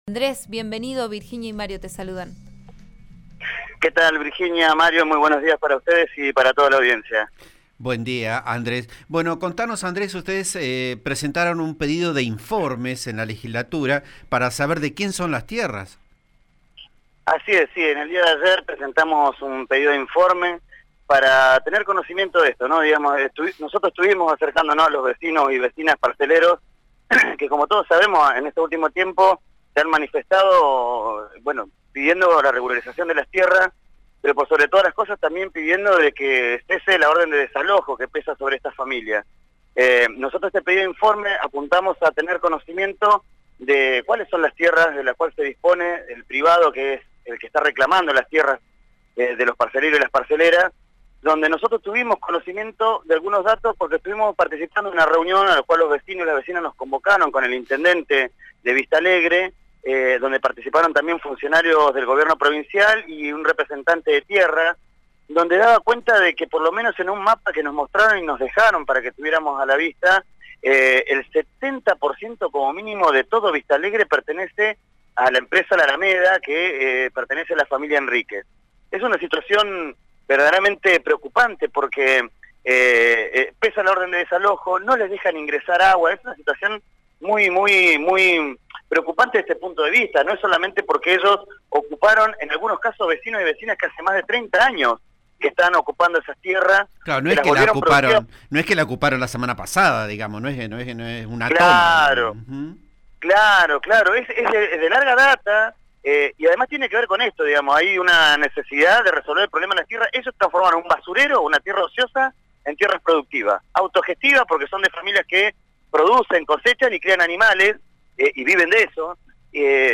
El diputado del FIT Andrés Blanco pasó por el aire de RN RADIO 89.3 para explicar la situación de 300 familias parceleras de Vista Alegre.